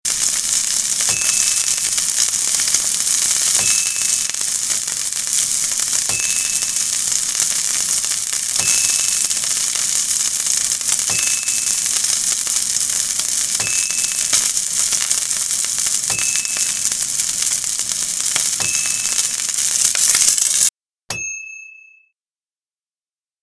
Sizzling, Frying, Grilling or Cooking Sounds (High #2)
A person cooking a piece of meat in a frying pan. Nothing but sizzling for the most part.
HeavySizzleinaFryingPannew.mp3